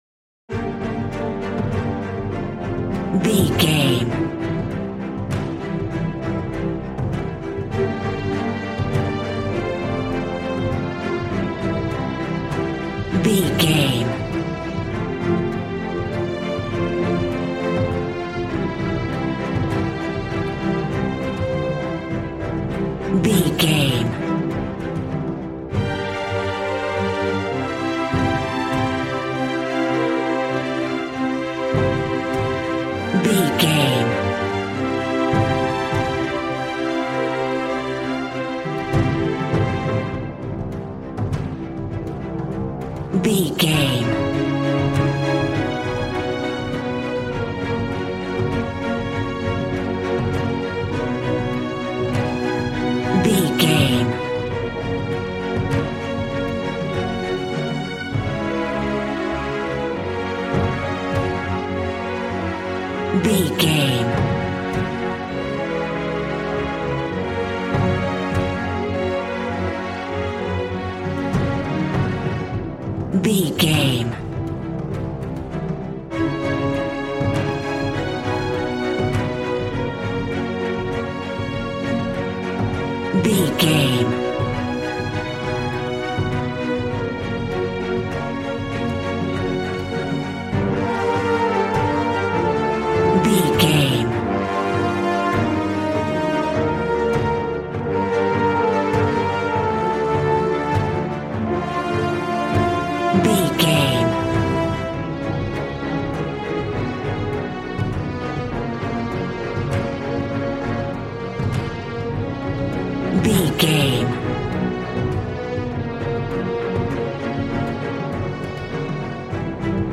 Aeolian/Minor
B♭
suspense
piano
synthesiser